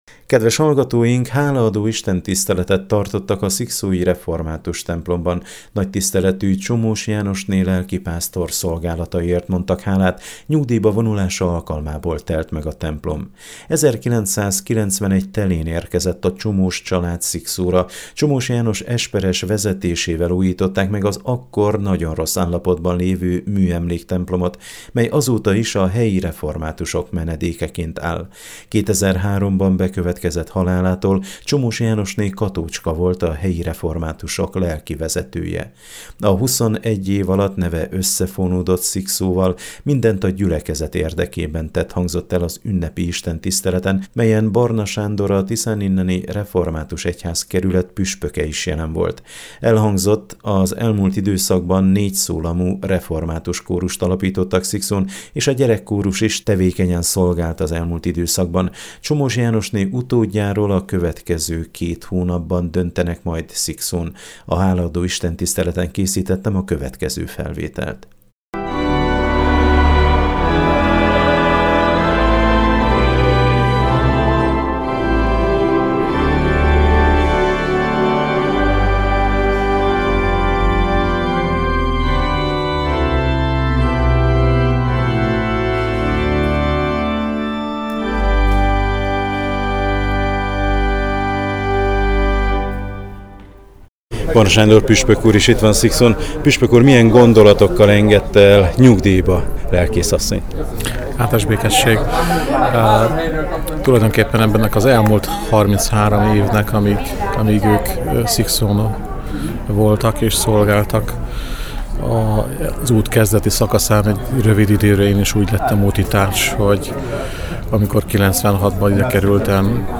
Hálaadó Istentiszteletet tartottak a szikszói református templomban.